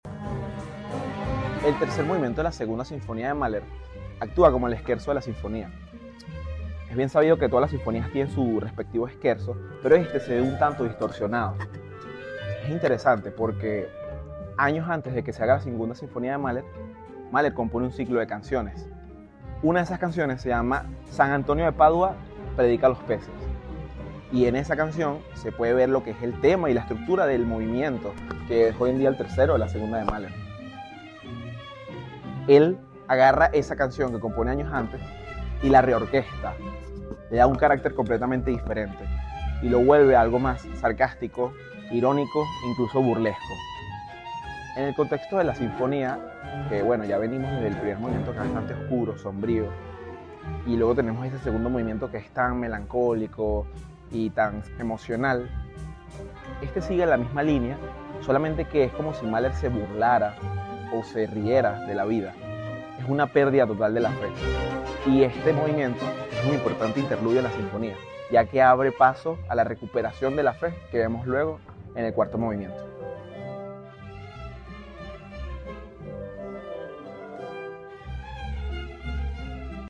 Te compartimos pequeños fragmentos de la 2da Sinfonía de G. Mahler.